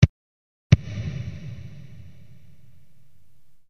バスドラム　原音→エフェクト音　63KBスネアドラム　原音→エフェクト音